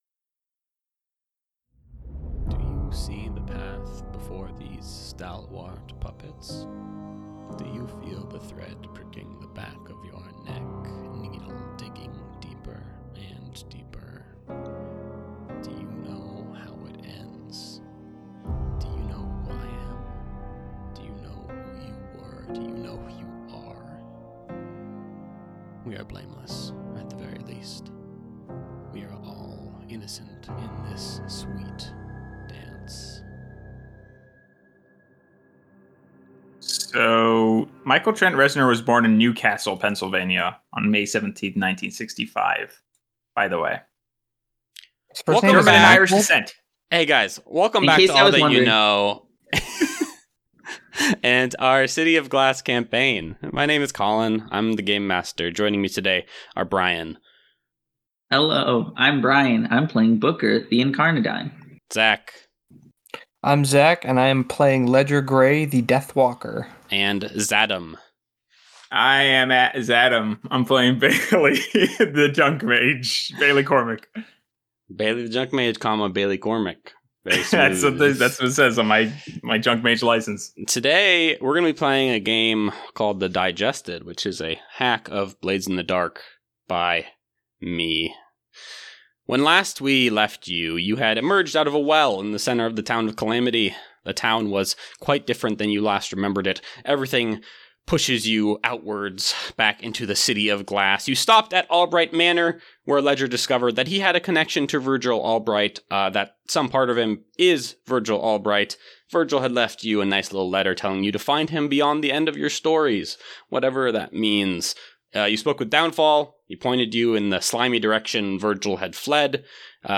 An actual play podcast combining horror, comedy, and a passion for storytelling into a memorable genre-bending show guaranteed to intrigue.